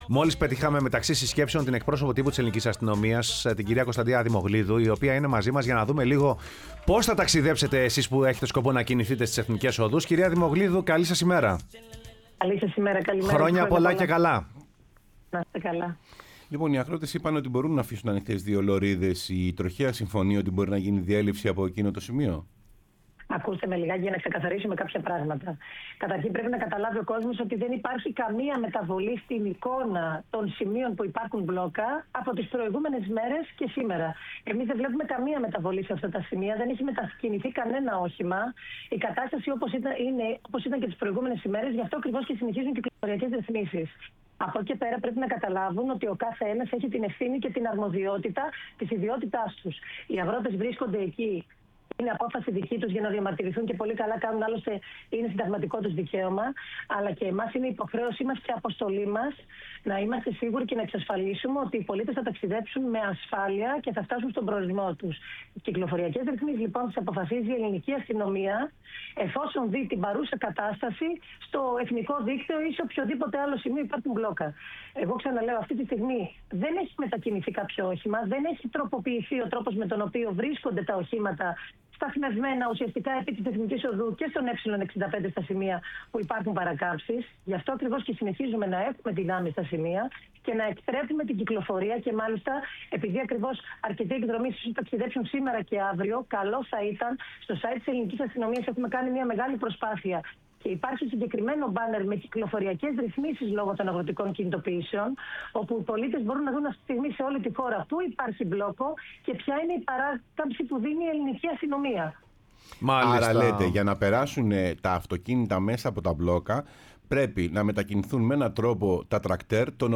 μίλησε στην εκπομπή Σεμνά και ταπεινά